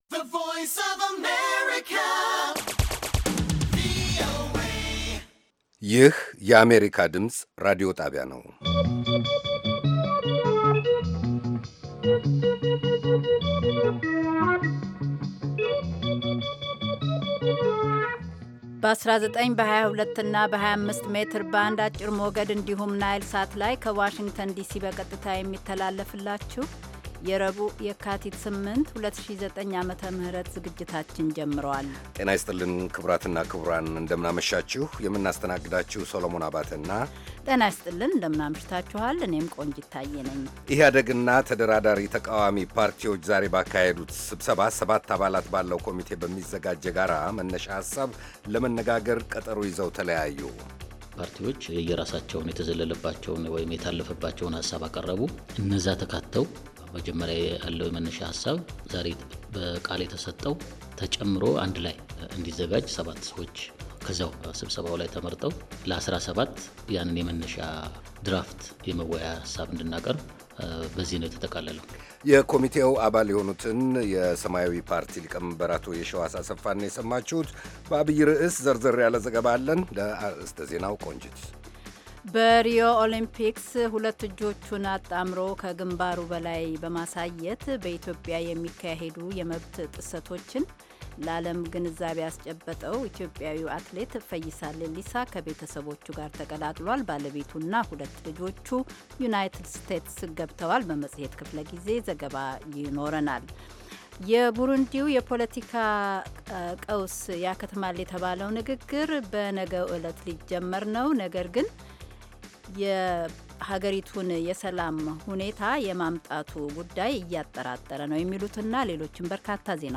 ረቡዕ፡-ከምሽቱ ሦስት ሰዓት የአማርኛ ዜና